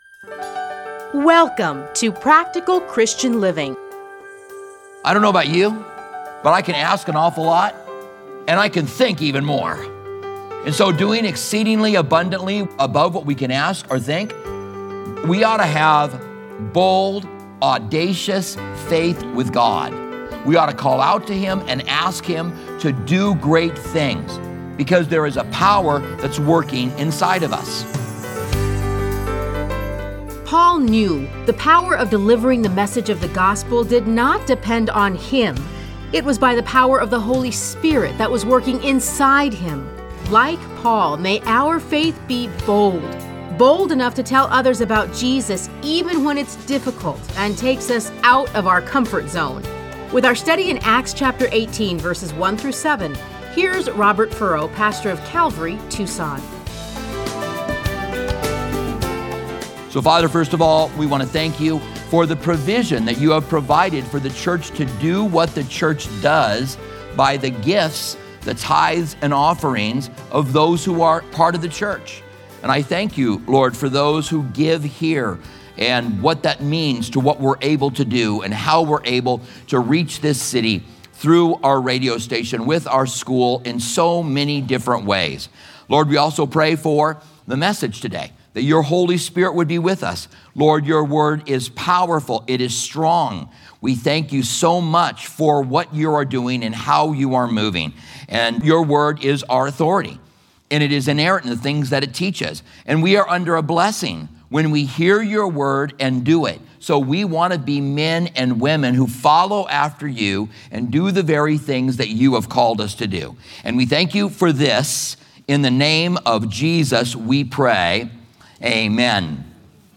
Listen to a teaching from Acts 18:1-17.